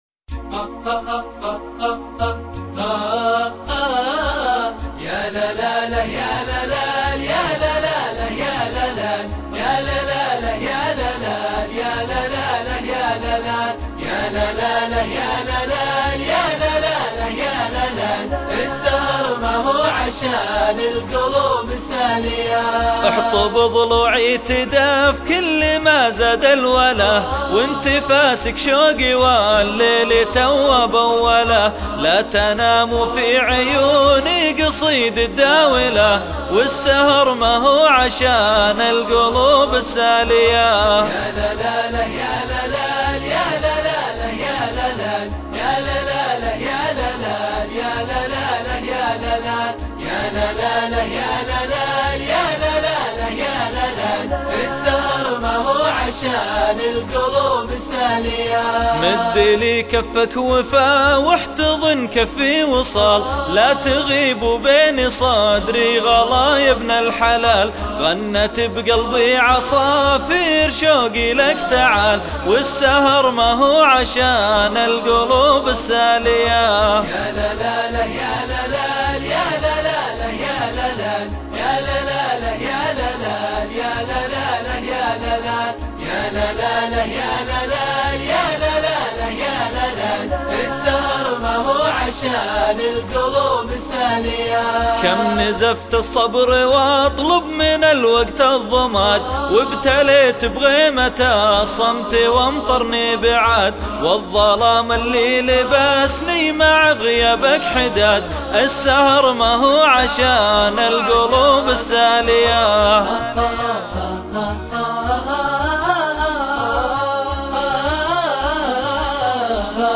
شيلات